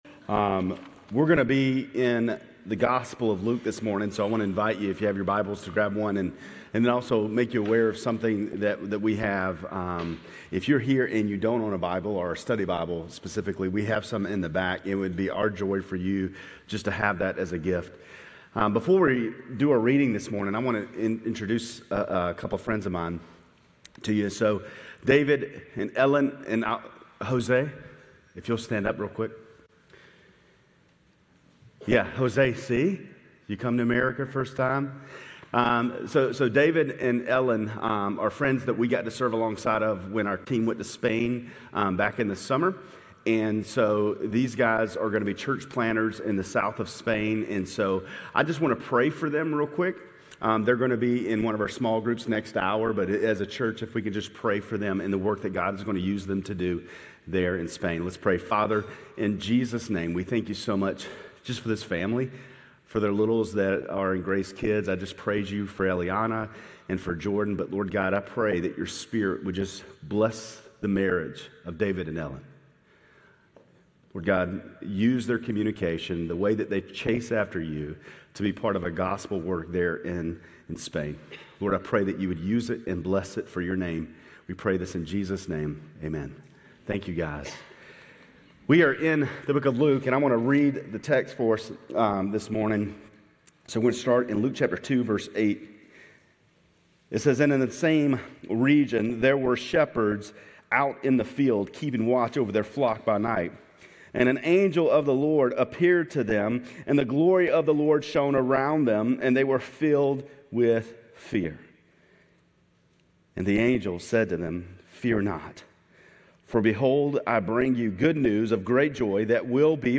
GCC-Lindale-December-10-Sermon.mp3